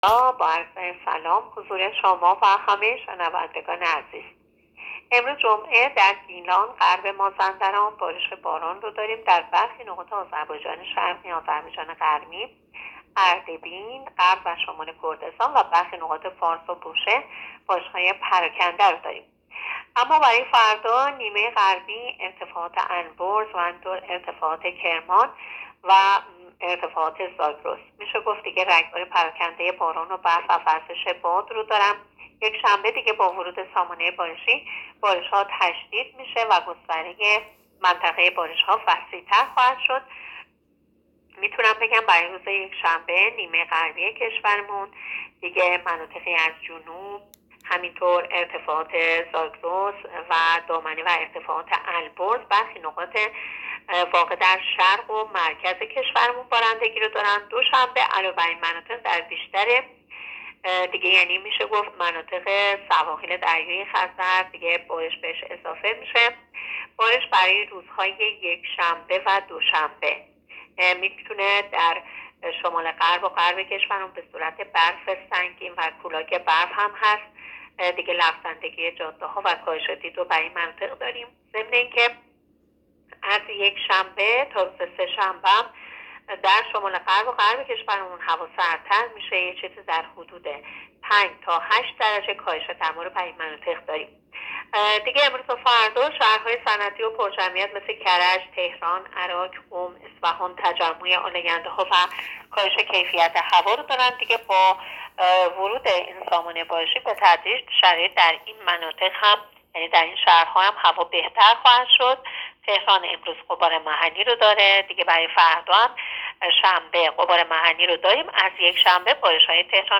گزارش رادیو اینترنتی از آخرین وضعیت آب و هوای ۵ دی؛